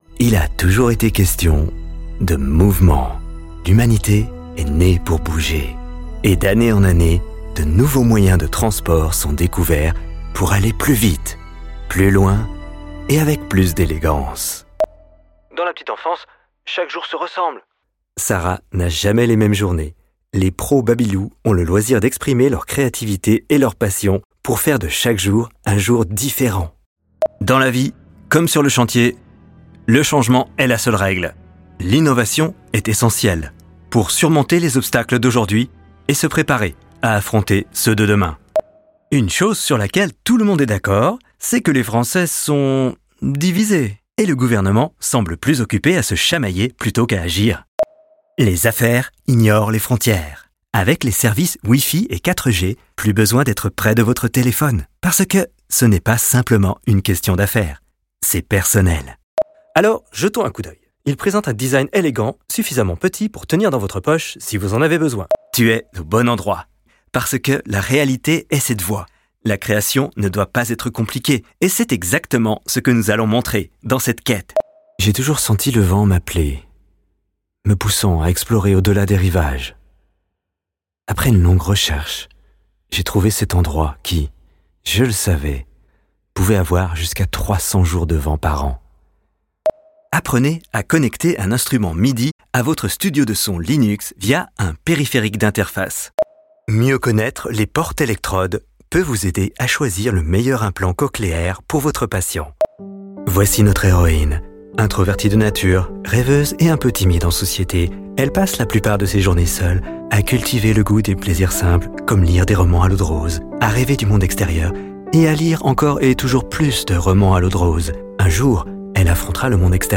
SHOWREEL